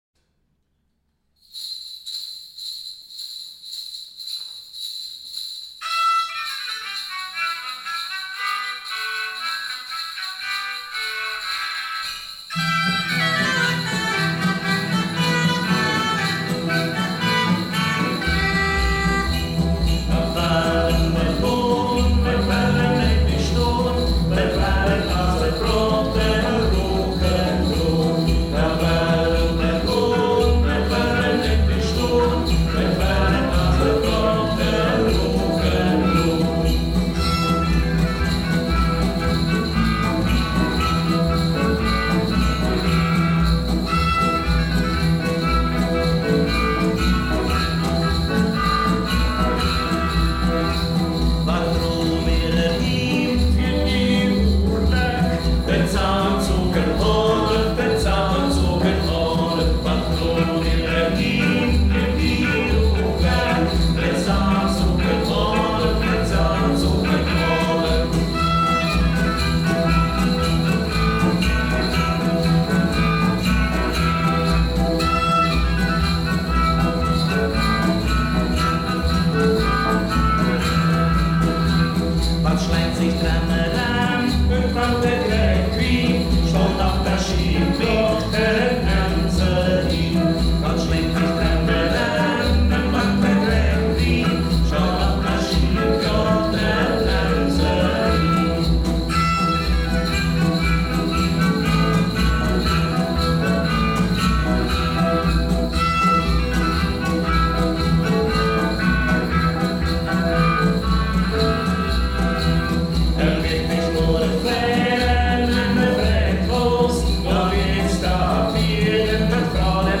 Volkslied
Umgangssächsisch
Ortsmundart: Schönberg